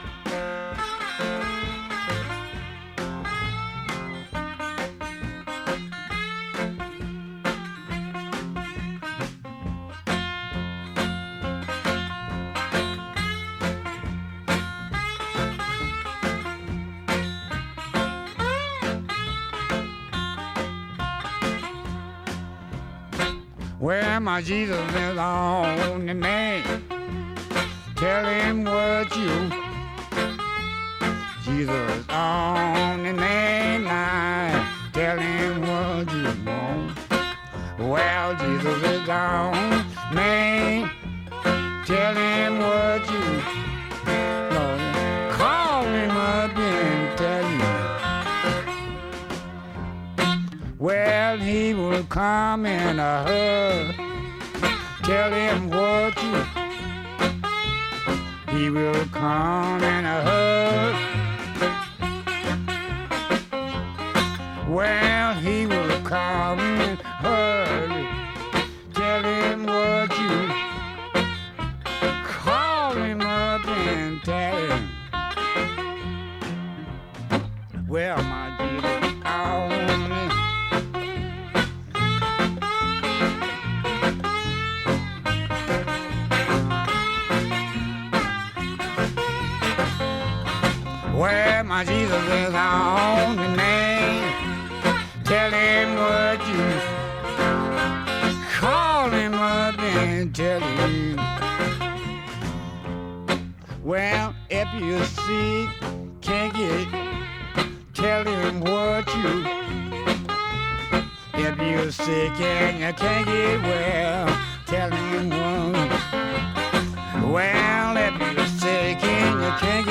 was an American hill country blues singer and guitar player.